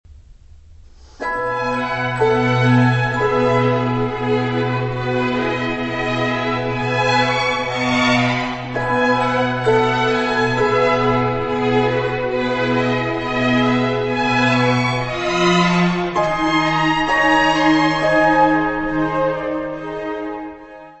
Music Category/Genre:  Classical Music